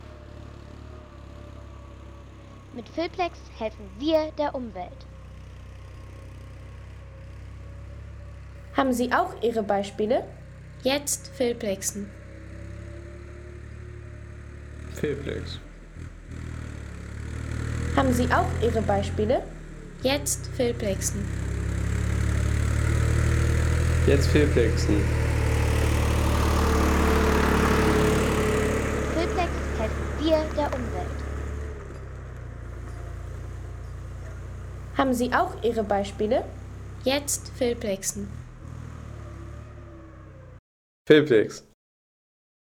Traktorfahrt während der Holzarbeiten im Wald
Technik - Traktoren